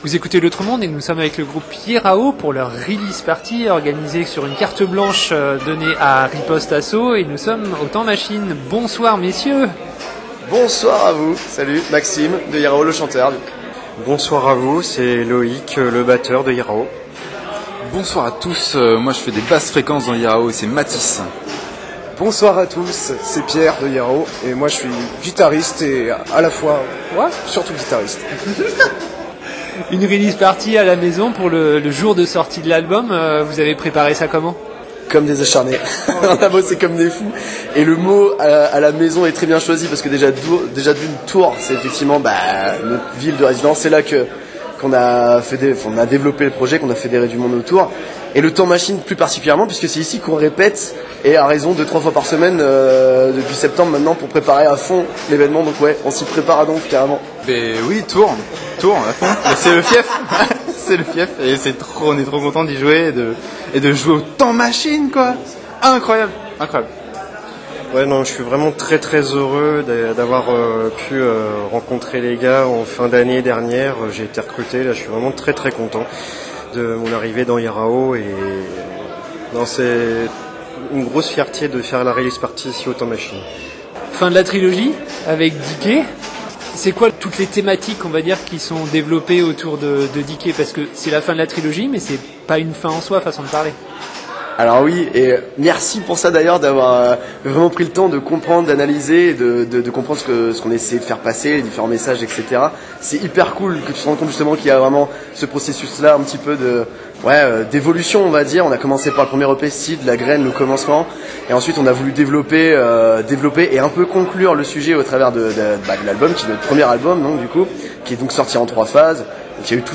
Le groupe a travaillé d'arrache-pied, comme il nous le précise dans leur interview enregistrée avant leur montée sur scène.